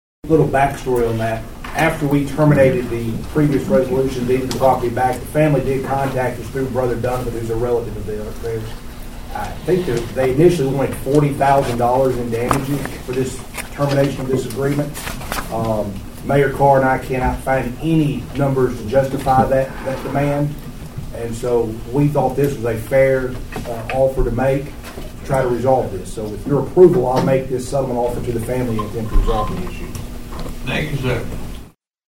County Attorney Steve Conley also addressed the proposed settlement.(AUDIO)